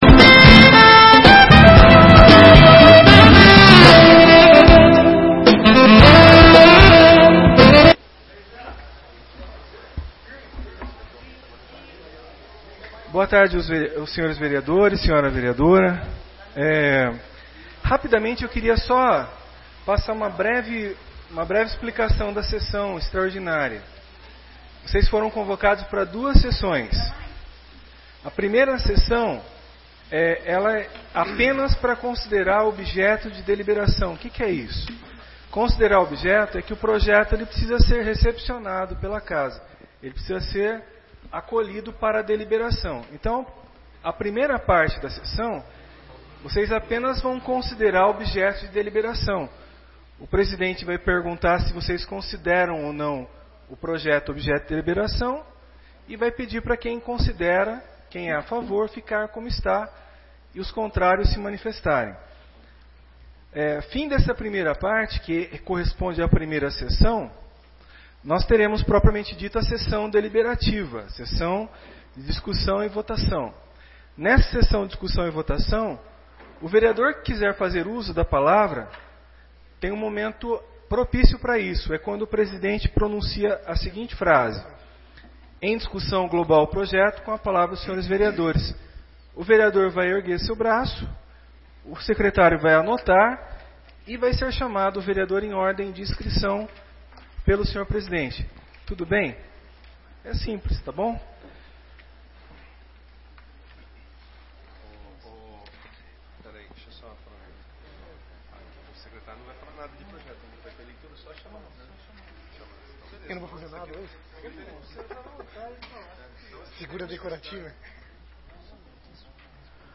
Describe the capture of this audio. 1ª e 2ª Sessões Extraordinárias de 2017